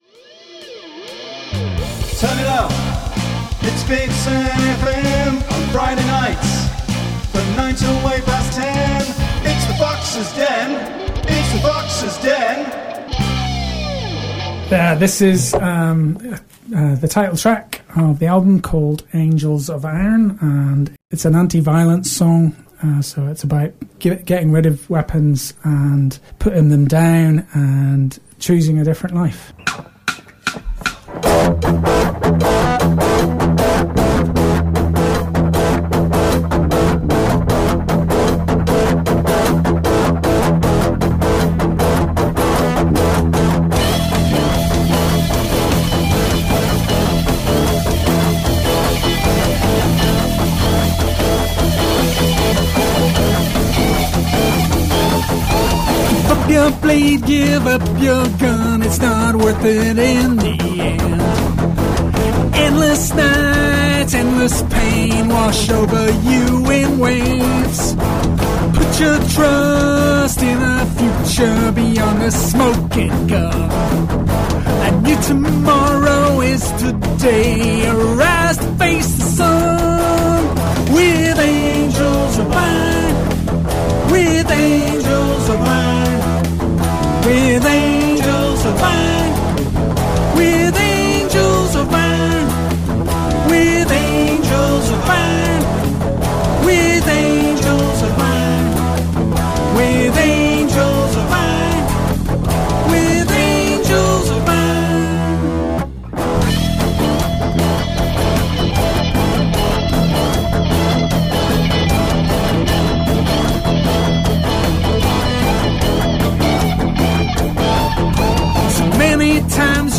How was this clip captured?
Live Recordings and Radio Sessions.